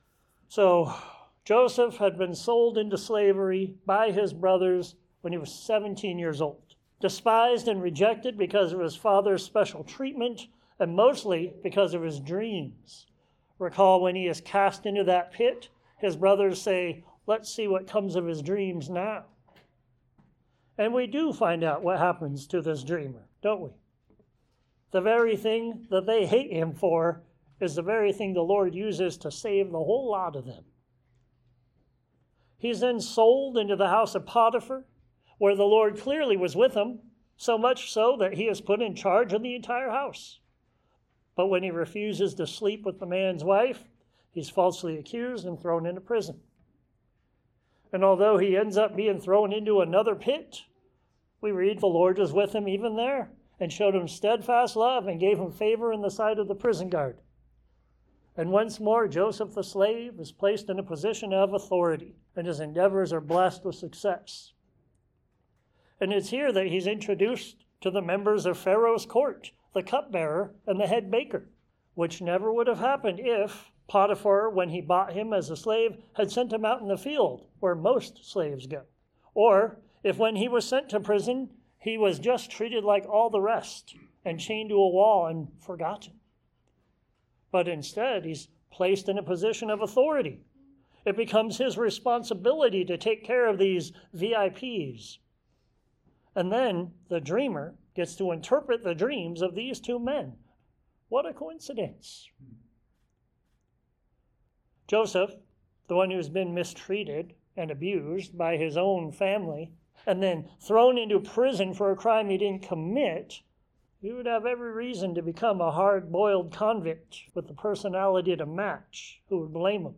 Genesis 40-41:45 From Prison to Palace Sermons Share this: Share on X (Opens in new window) X Share on Facebook (Opens in new window) Facebook Like Loading...